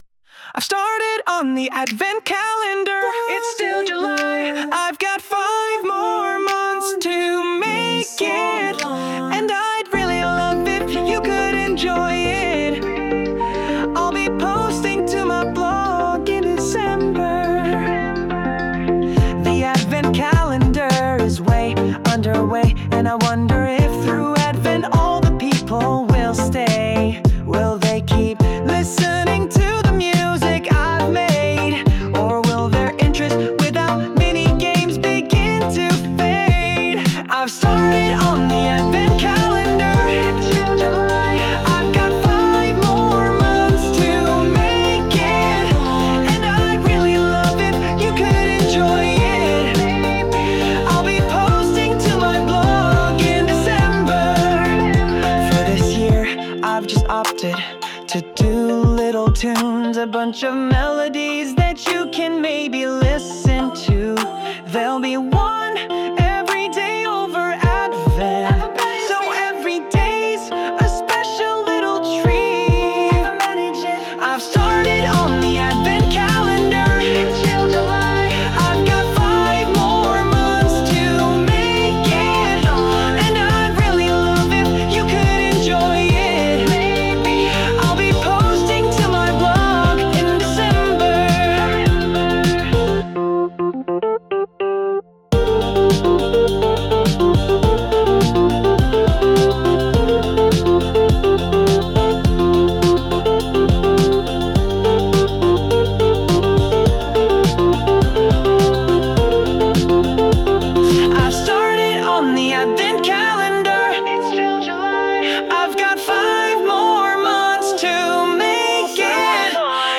Sound Imported : Recorded Sleighbells
Sung by Suno